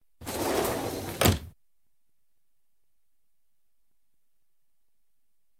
doorsclosed.ogg